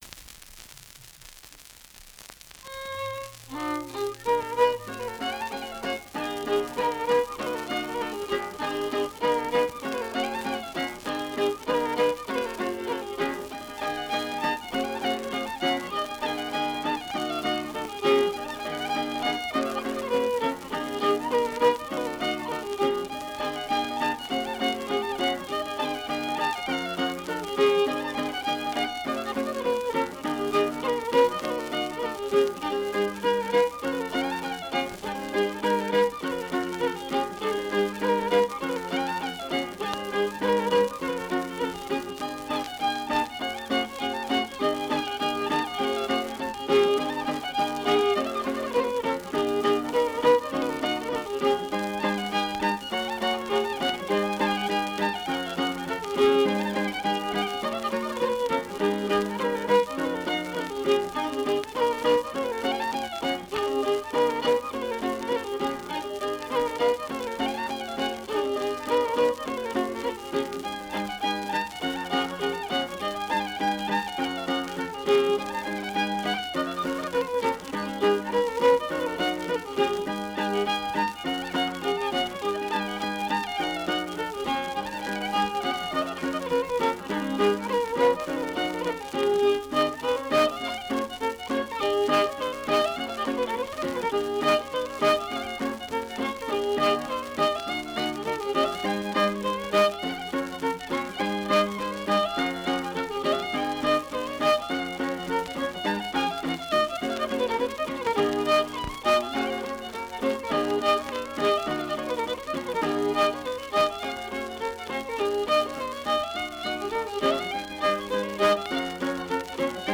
Medley of Reels
Violin with Guitar Accompaniment
Keywords: Irish Traditional Music
digitised from an original 78RPM shellac record